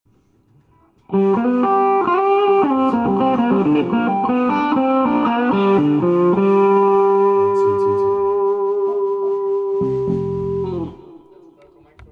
I think it's a BF modded into a #102. It really sounds great IMHO but from what I can gather a #124 is generally better suited to Strats.